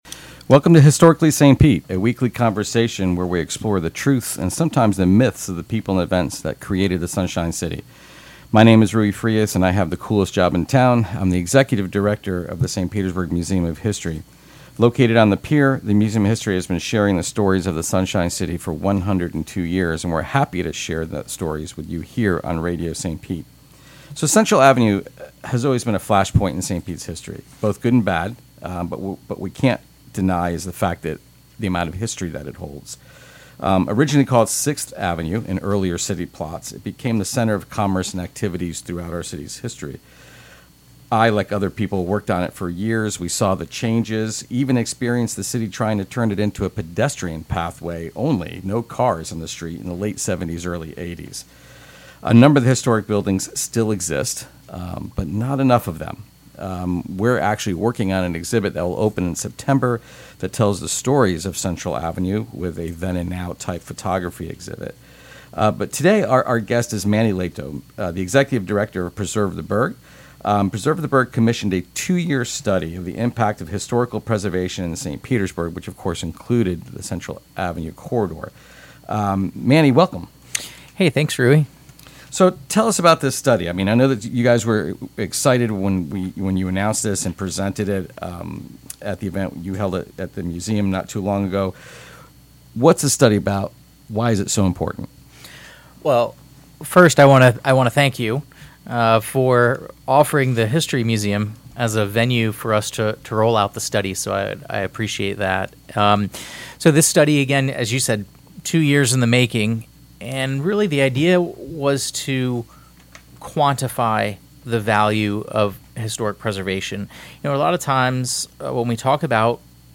weekly conversation